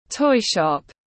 Cửa hàng bán đồ chơi tiếng anh gọi là toy shop, phiên âm tiếng anh đọc là /tɔɪ ʃɒp/.
Toy shop /tɔɪ ʃɒp/
Toy-shop.mp3